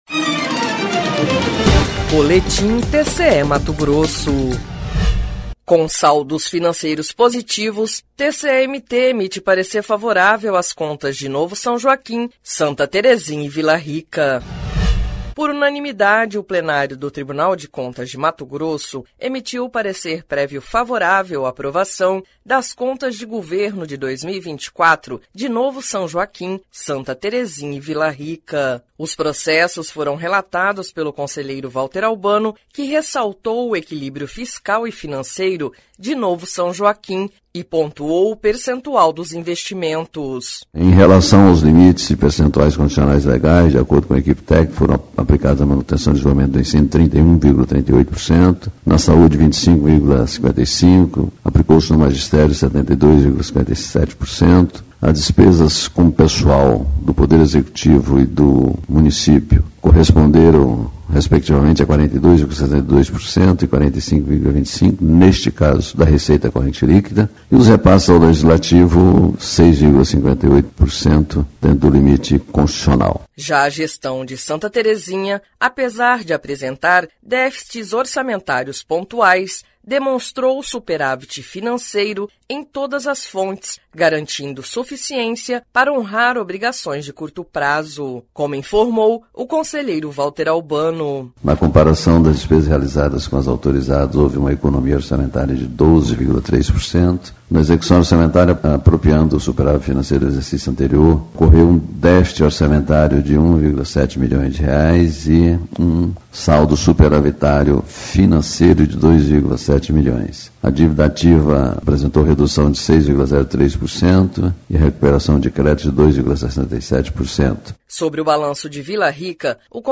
Sonora: Valter Albano – conselheiro do TCE-MT